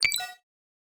Futuristic Sounds (27).wav